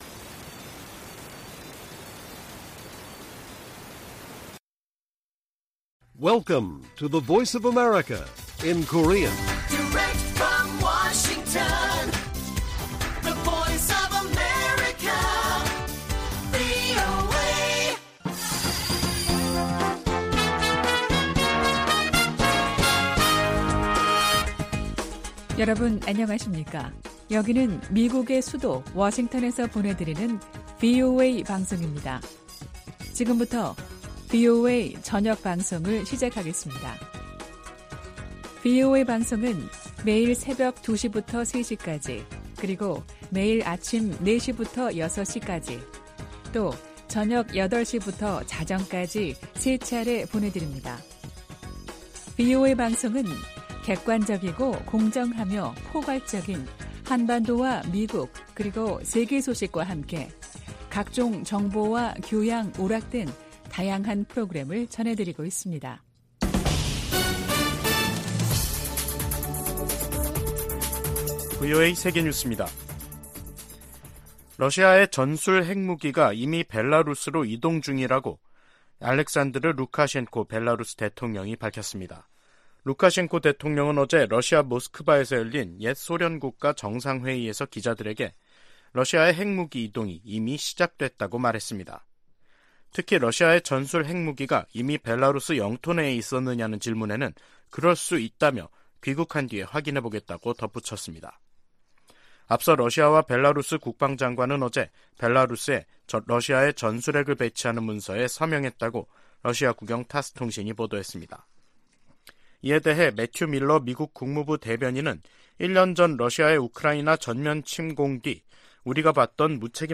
VOA 한국어 간판 뉴스 프로그램 '뉴스 투데이', 2023년 5월 26일 1부 방송입니다. 한국이 자력으로 실용급 위성을 궤도에 안착시키는데 성공함으로써 북한은 우주 기술에서 뒤떨어진다는 평가가 나오고 있습니다. 워싱턴 선언은 한국에 대한 확정억제 공약을 가장 강력한 용어로 명시한 것이라고 미 고위 당국자가 평가했습니다. 미국은 중국의 타이완 공격을 억제하기 위해 동맹국들과 공동 계획을 수립해야 한다고 미 하원 중국특별위원회가 제언했습니다.